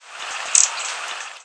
Buff-bellied Hummingbird Amazilia yucatanensis
Flight call description A loud, abrupt, smacking "tsk" often repeated in a series of evenly-spaced notes or in a very rapid rattle-like series.
Fig.1. Texas December 17, 2001 (WRE).
Bird in flight.